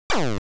Sound effect of "Item Sprout" in Wario Land: Super Mario Land 3.